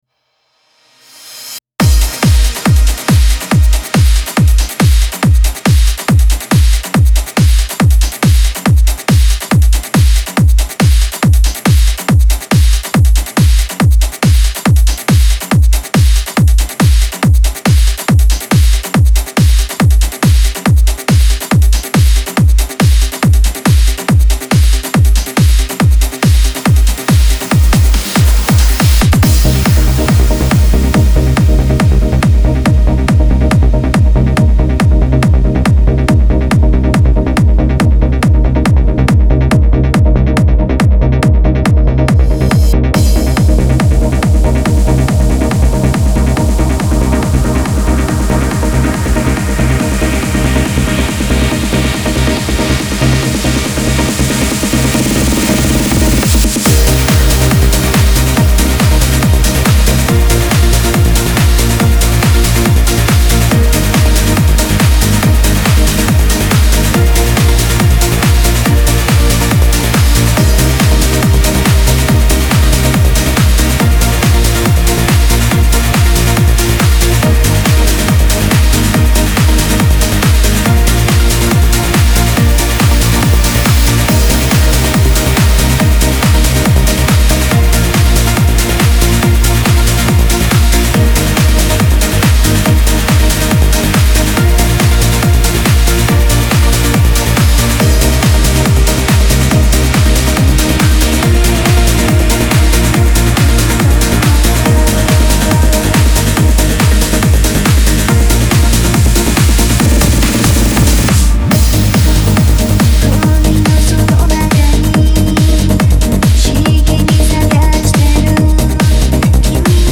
Genre(s): Trance